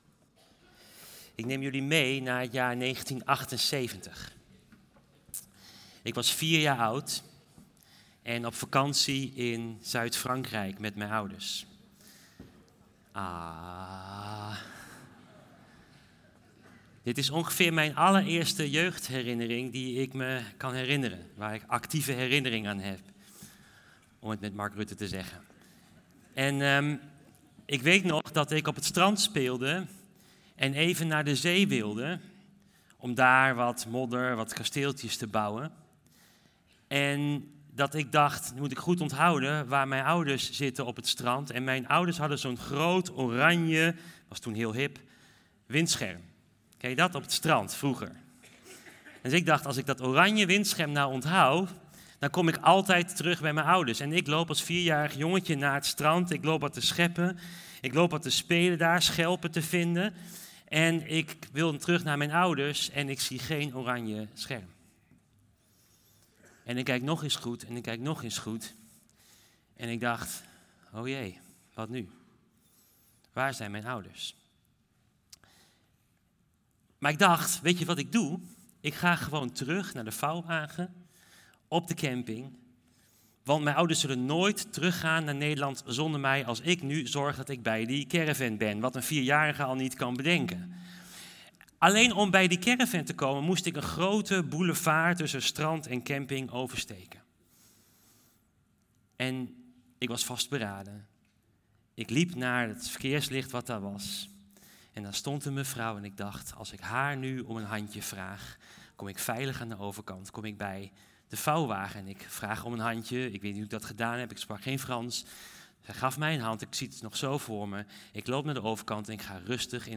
Preken De bekende onbekende Gepubliceerd: 22 november 2025 De Heilige Geest - zo zegt de kerk al eeuwen - is deel van de 'drie-eenheid' van God.